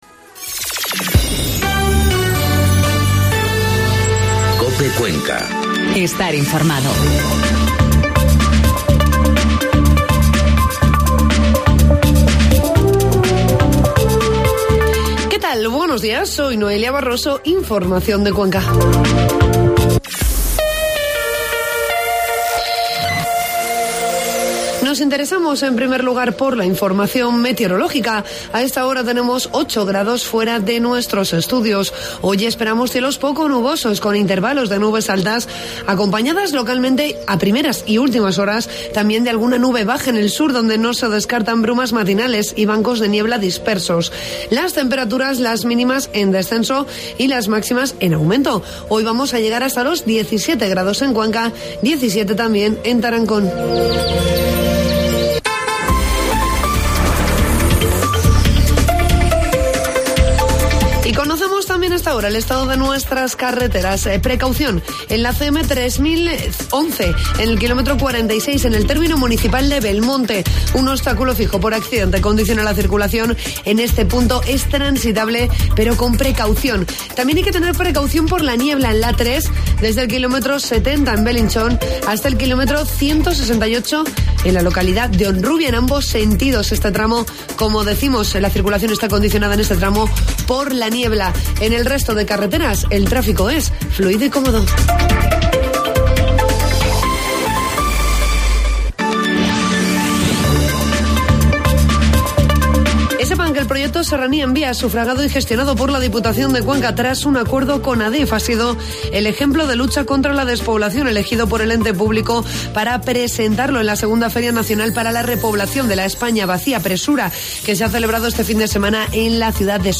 Informativo matinal COPE Cuenca 12 de noviembre